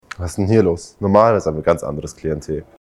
Dieses Mikrofon nimmt den Schall aus der unmittelbaren Umgebung auf.
Ansteckmikro - Situativer O-Ton
Clipmikro.mp3